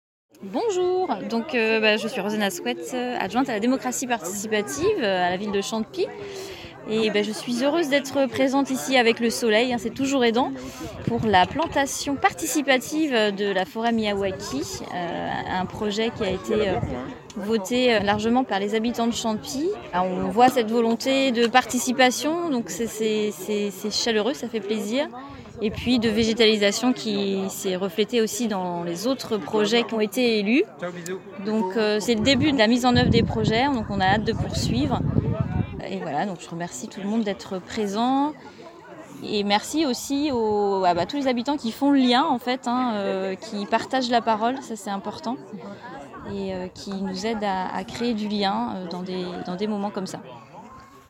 par élue à la démocratie participative